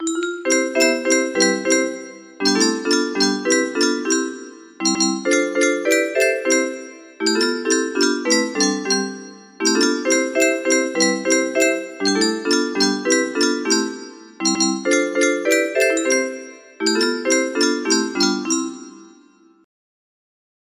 Balon ku ada lima aransemen 4 suara